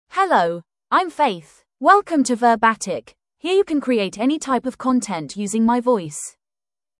Faith — Female English (United Kingdom) AI Voice | TTS, Voice Cloning & Video | Verbatik AI
FemaleEnglish (United Kingdom)
Voice sample
Female
Faith delivers clear pronunciation with authentic United Kingdom English intonation, making your content sound professionally produced.